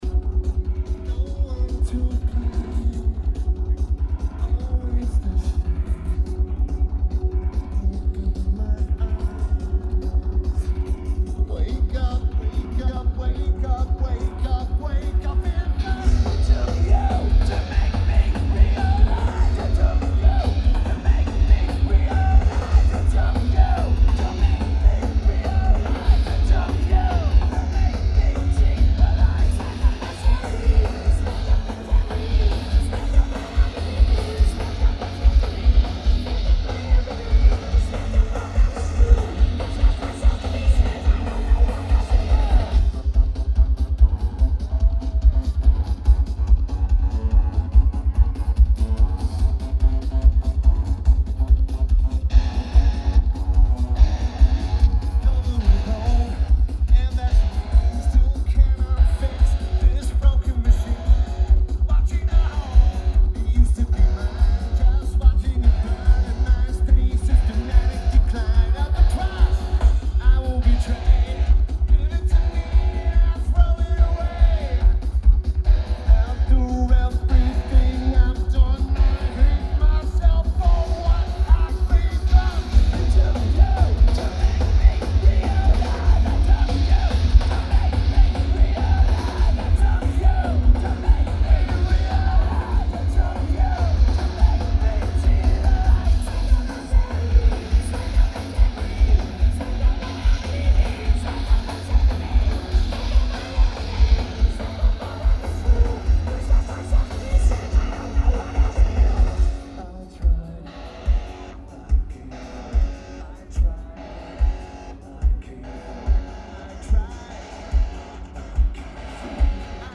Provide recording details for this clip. Primavera Sound LA Lineage: Audio - AUD (SP-CMC-8 (w/mods) + Edirol R07)